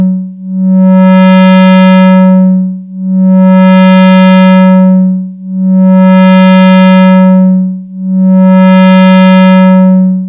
vcf lfo tri mod low res.mp3